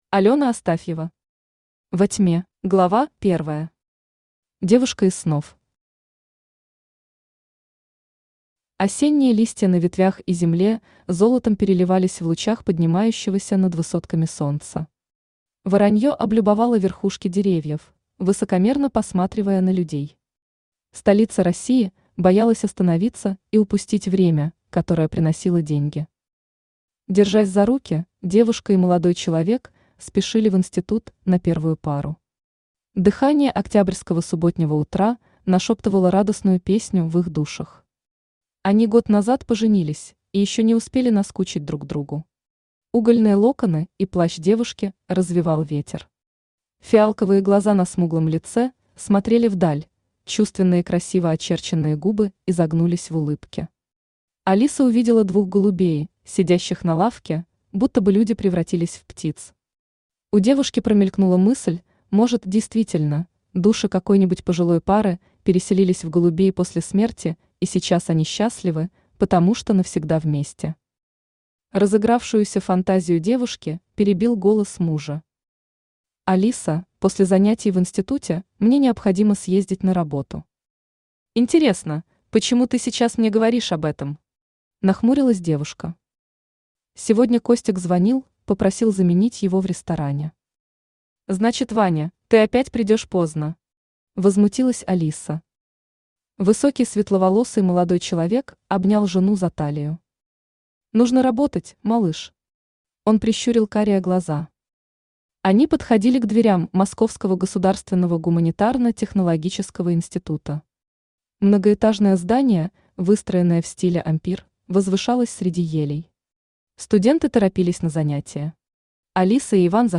Аудиокнига Во тьме | Библиотека аудиокниг
Aудиокнига Во тьме Автор Алёна Астафьева Читает аудиокнигу Авточтец ЛитРес.